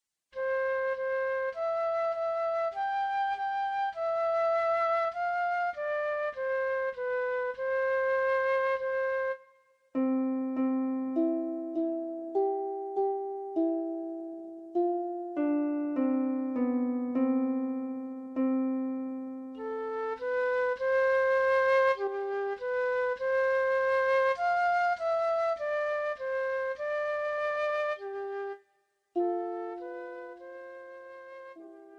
for lever harp and flute (or C instrument)